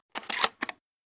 remington870_load.ogg